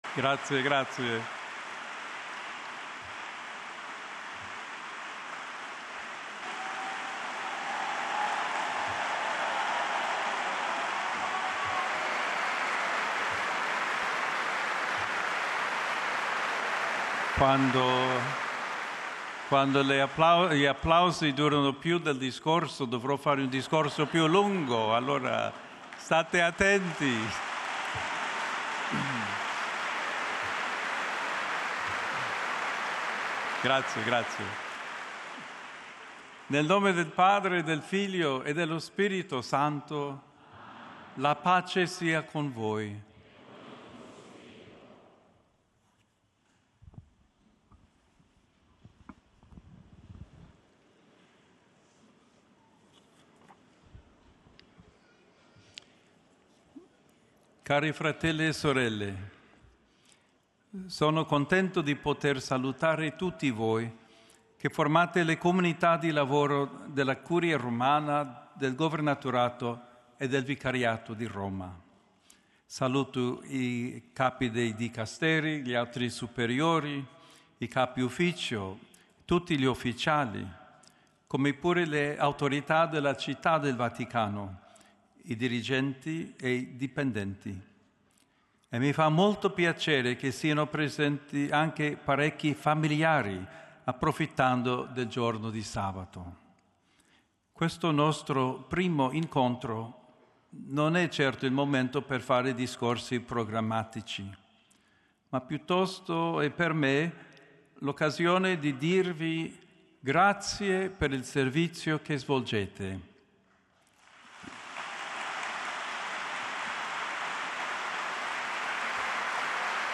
This podcast offers the public speeches of the Holy Father, in their original languages.